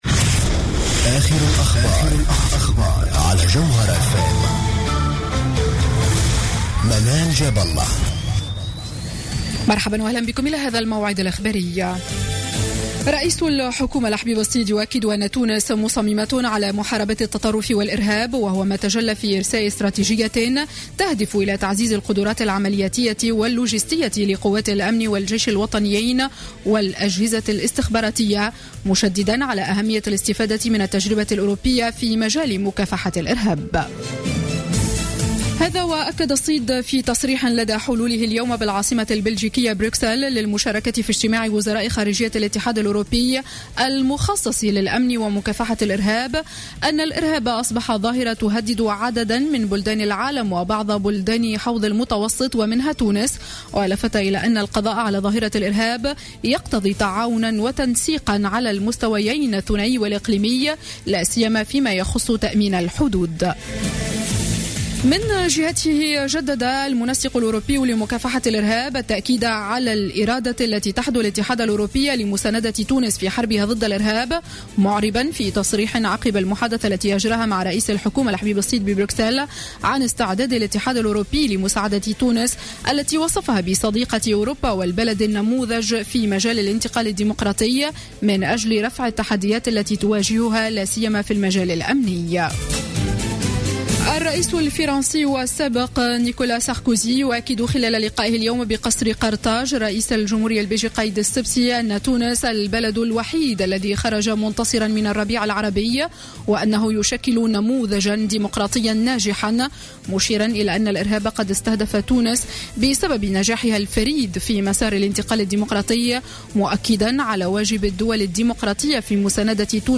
نشرة أخبار السابعة مساء ليوم الاثنين 20 جويلية 2015